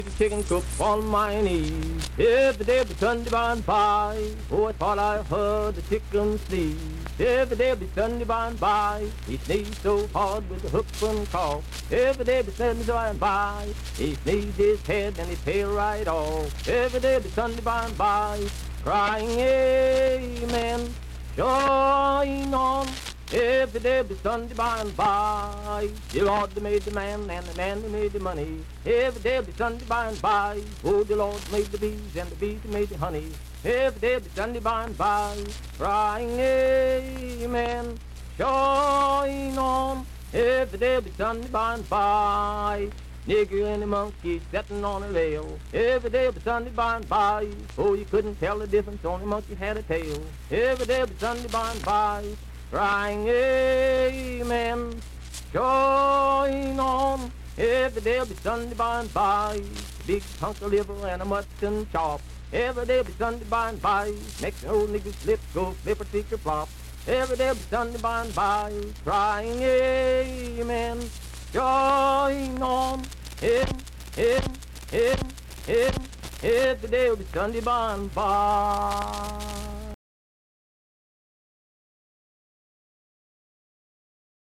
Unaccompanied vocal performance
Minstrel, Blackface, and African-American Songs
Voice (sung)
Vienna (W. Va.), Wood County (W. Va.)